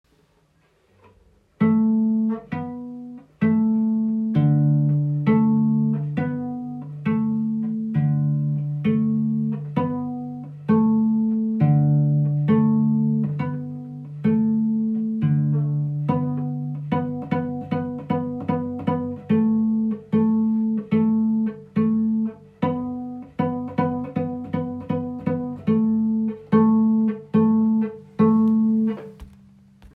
Beispiele Cello
Wind an Bord (pizzicato - gezupft)